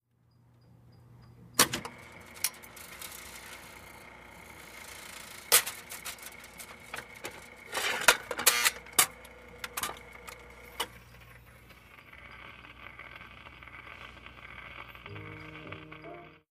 Jukebox
Jukebox, Cu, W Coin Insert, Mechanical Activity, Buzz, Record Begins To Play.